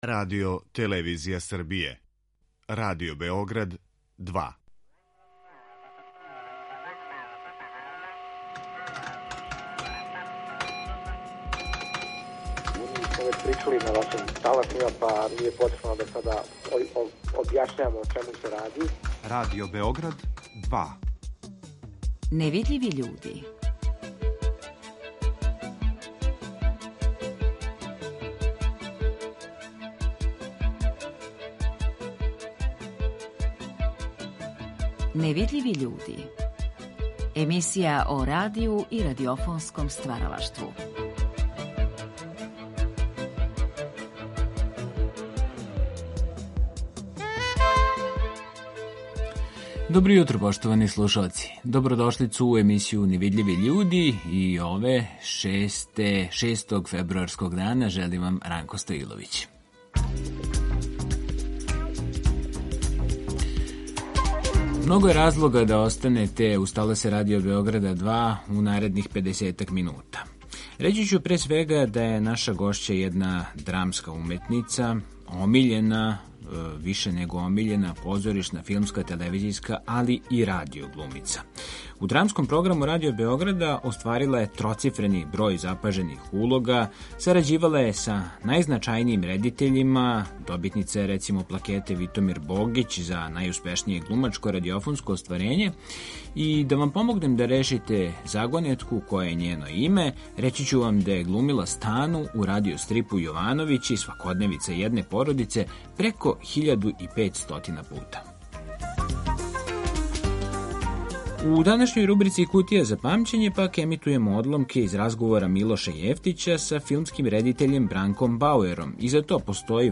Наша гошћа је драмска уметница Рената Улмански , омиљена позоришна, филмска и телевизијска, али и радио-глумица свих генерација.
Бауер, од чијег рођења за који дан обележавамо једно столеће, говорио је за емисију Гост Другог програма 1984. године.